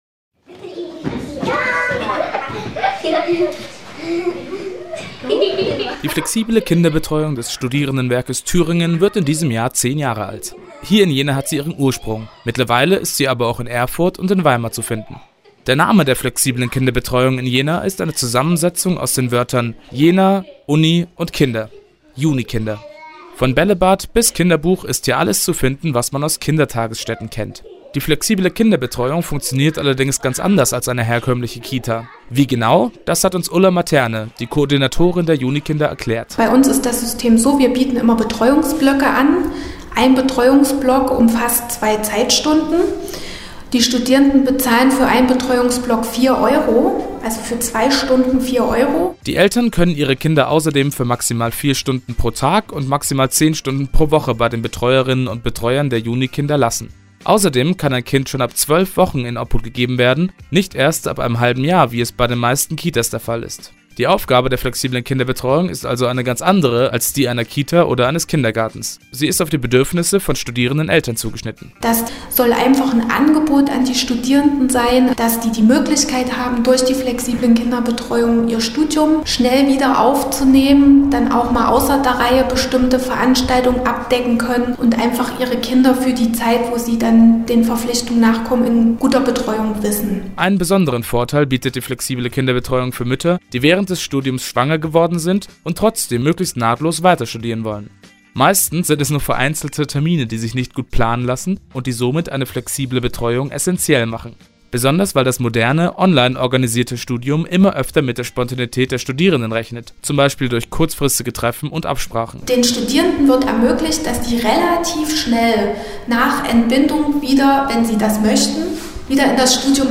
Im Gespräch: JUniKinder – Die flexible Kinderbetreuung – Campusradio Jena